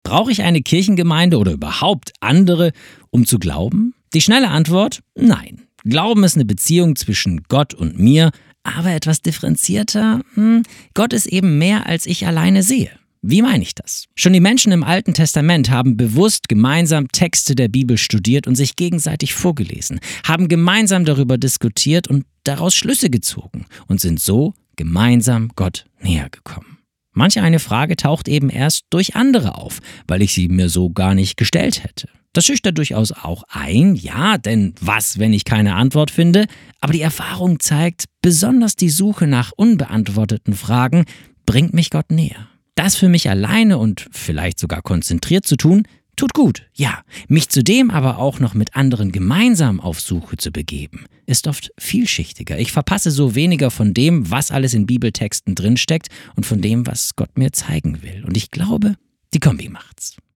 Startseite > andacht > Gott, ich und die anderen!